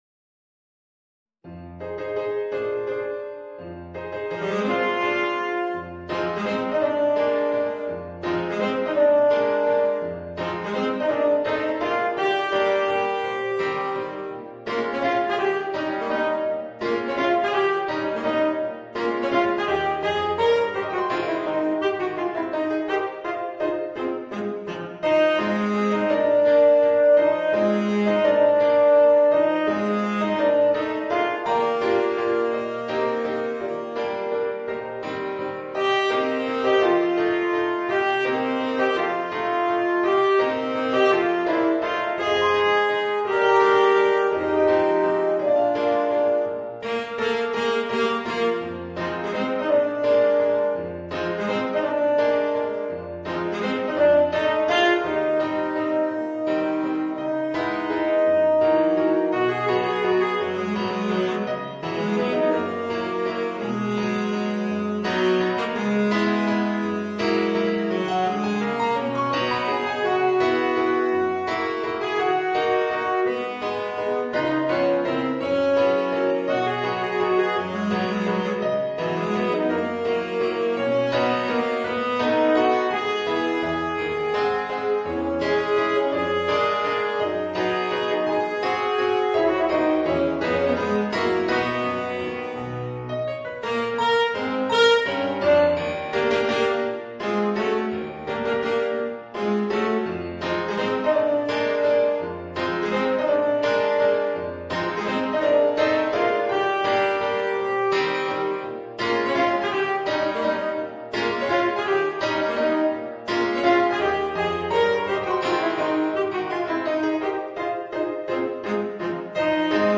Saxophone ténor & piano